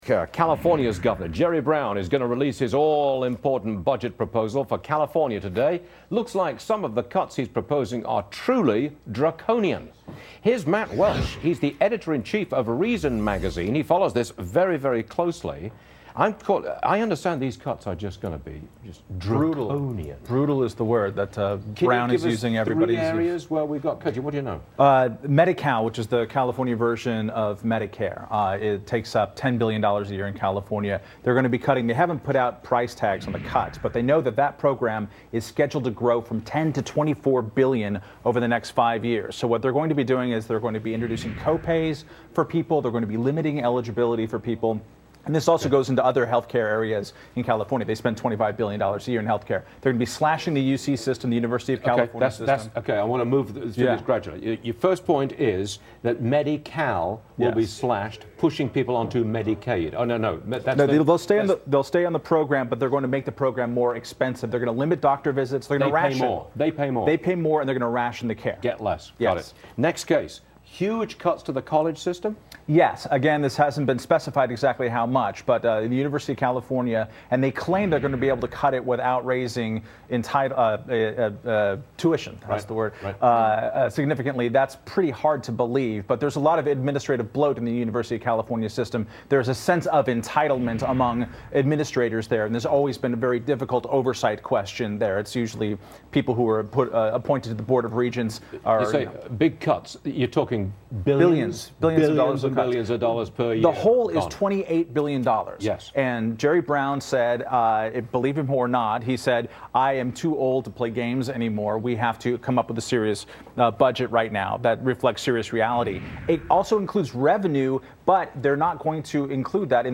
appeared on Varney & Co. to discuss the severe budget cuts that California's new governor Jerry Brown is proposing and why Brown, a Democrat, might just have the political clout to succeed where former Gov. Schwarzenegger failed.